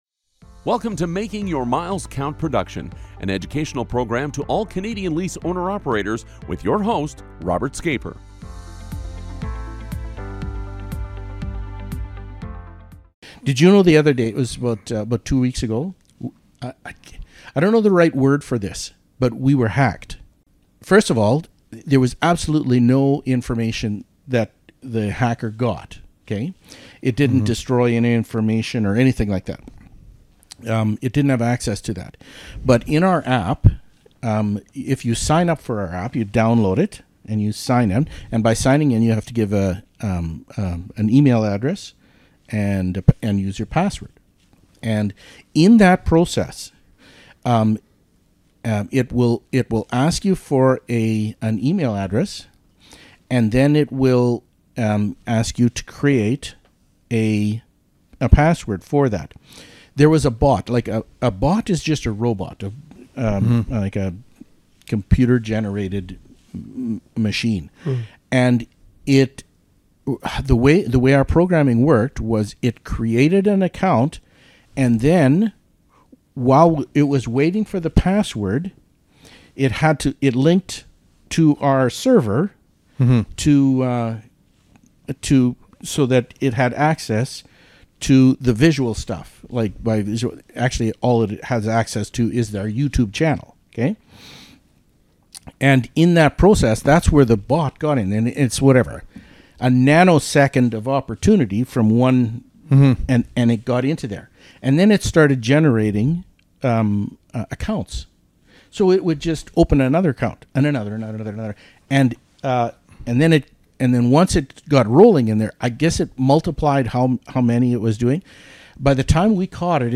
Enjoy this bonus chat! Sometimes the best conversations are the ones that weren’t planned.